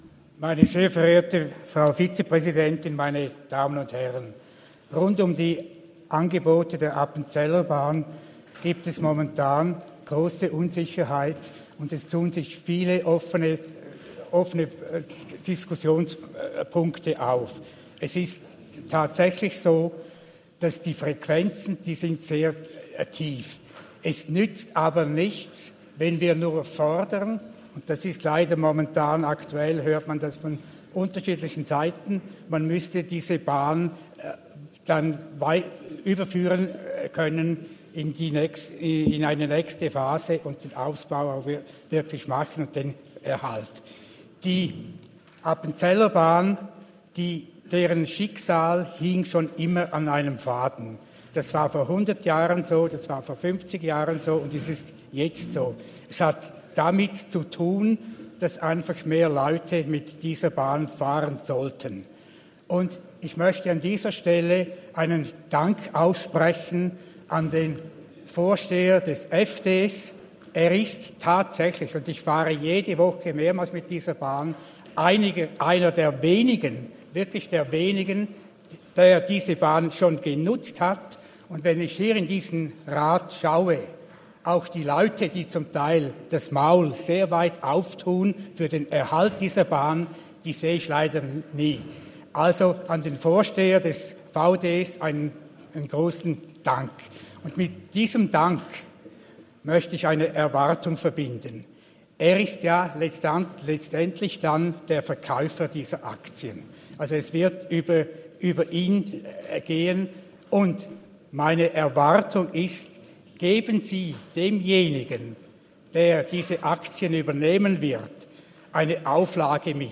27.11.2023Wortmeldung
Session des Kantonsrates vom 27. bis 29. November 2023, Wintersession